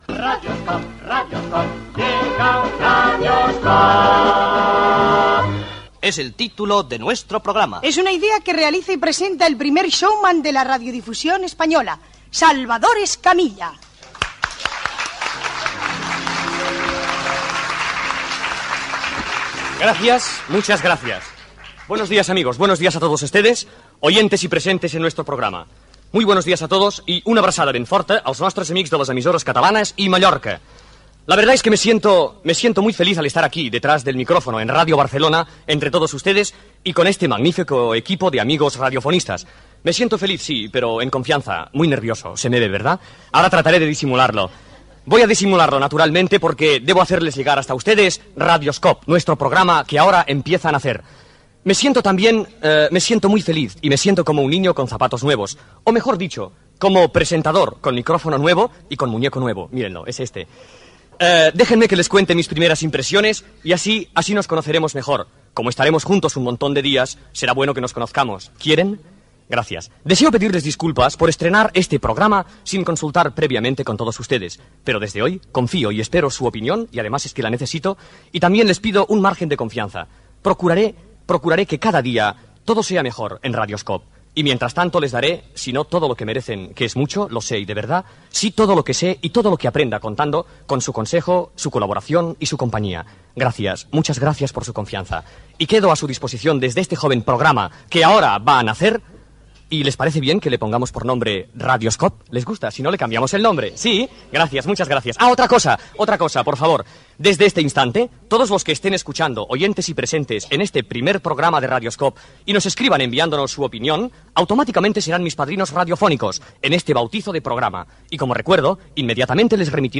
Careta. Sensacions de Salvador Escamilla en la primera edició del programa. Demanda de cartes .
Entreteniment
Presentador/a